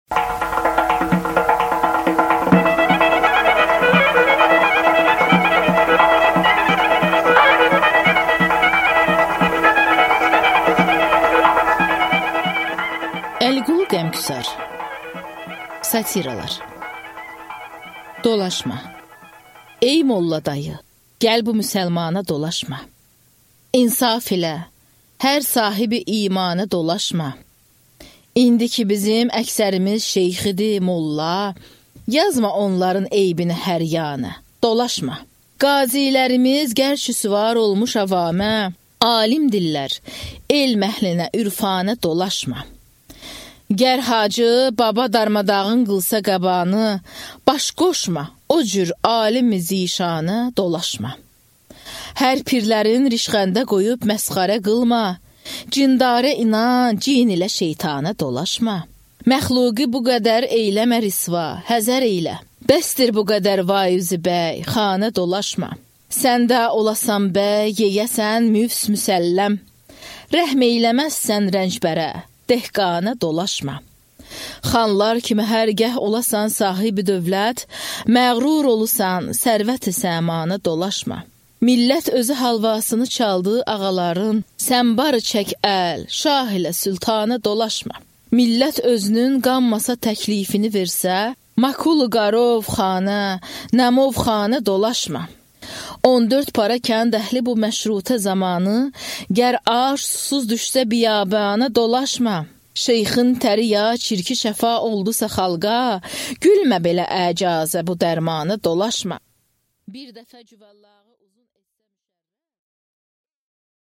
Аудиокнига Satiralar | Библиотека аудиокниг
Прослушать и бесплатно скачать фрагмент аудиокниги